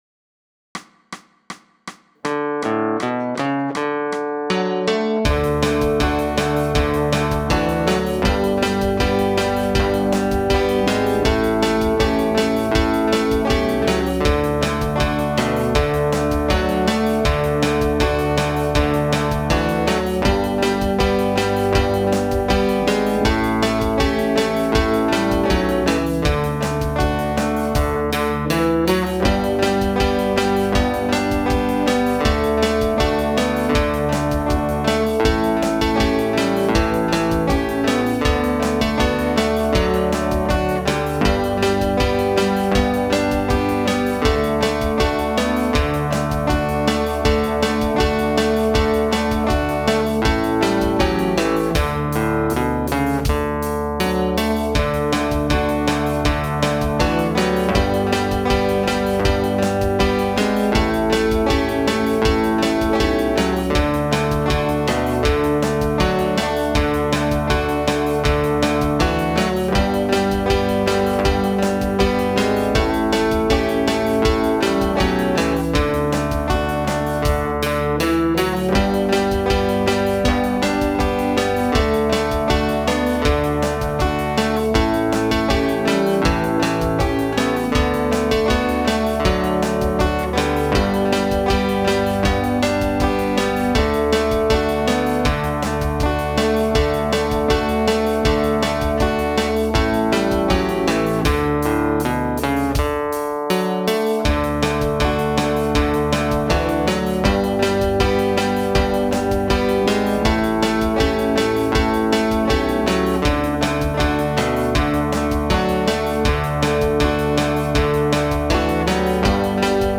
Jetzt kannst Du die Begleitung zu Bass, Schlagzeug und zweiter Rhythmusgitarre spielen und dazu singen.